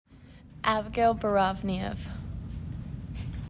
Pronunciation: ab ih GALE bore AHV knee EV